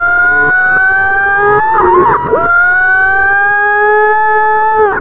ALARMREV  -R.wav